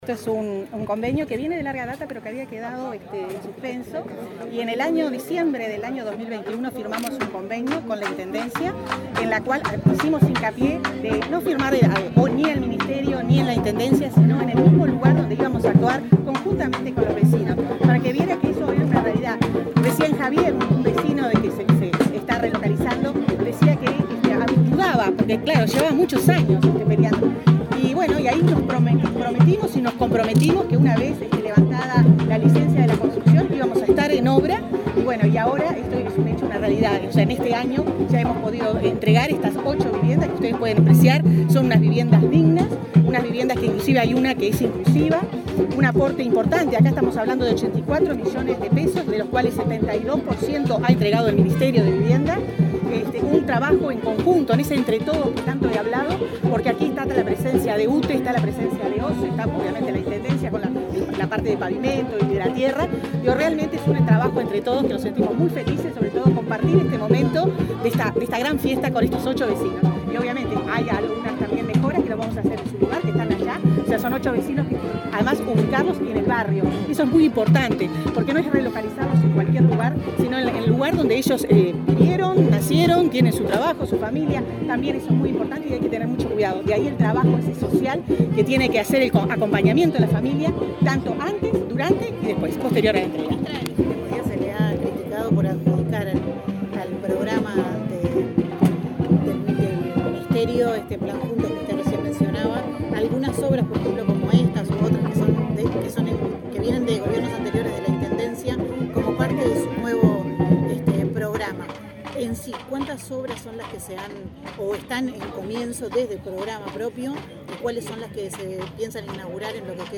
Declaraciones de la ministra de Vivienda, Irene Moreira
La ministra de Vivienda, Irene Moreira, encabezó la inauguración de casas en el barrio Campichuelo e Itapé, en Montevideo. Luego dialogó con la prensa